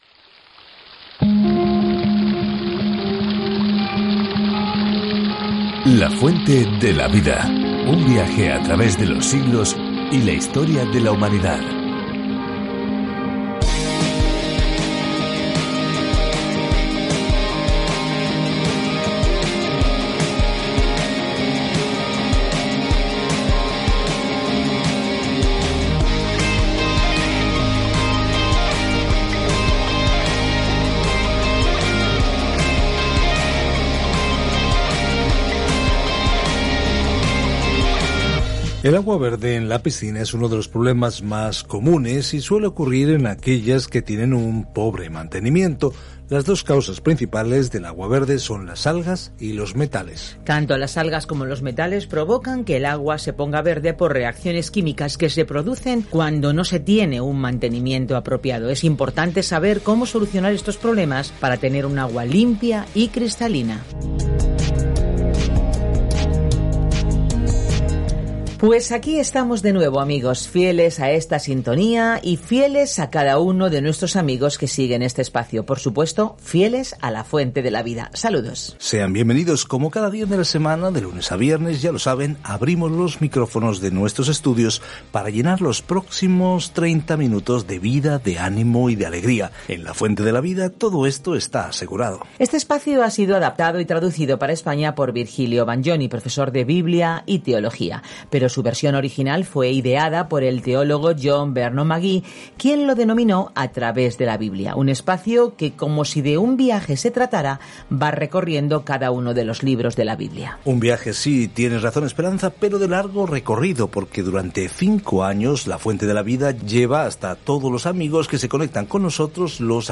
Escritura OSEAS 1:1 Iniciar plan Día 2 Acerca de este Plan Dios usó el doloroso matrimonio de Oseas como ilustración de cómo se siente cuando su pueblo le es infiel, y aun así se compromete a amarlos. Viaja diariamente a través de Oseas mientras escuchas el estudio de audio y lees versículos seleccionados de la palabra de Dios.